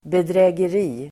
Uttal: [bedrä:ger'i:]